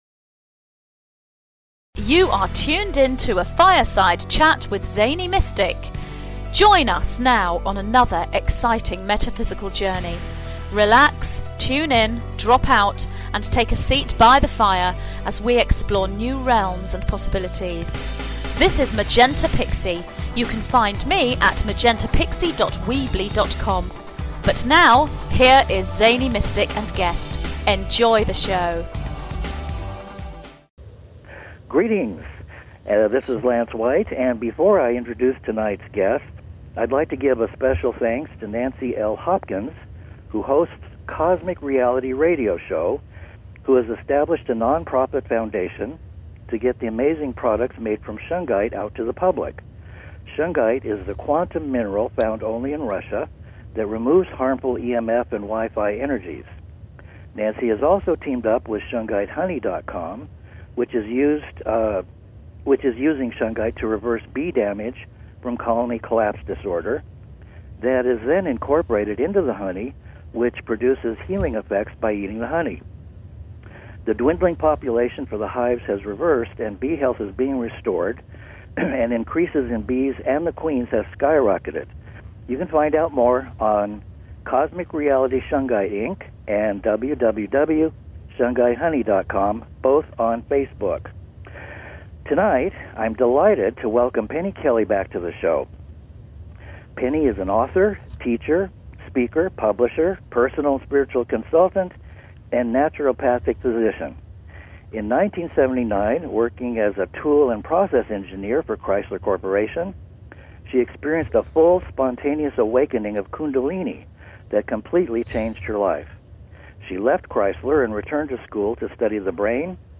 Talk Show Episode
Subjects included the technology of Crop Circles, Plasma energy, the Deep State, and we were ending on Orbs when Skype dropped!